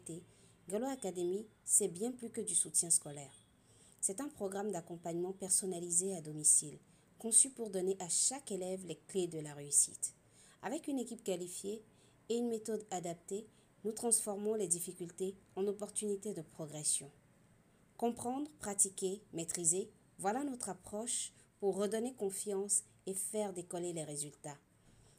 Voix off
Voix - Soprano